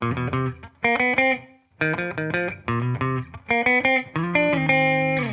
Index of /musicradar/sampled-funk-soul-samples/90bpm/Guitar
SSF_TeleGuitarProc2_90C.wav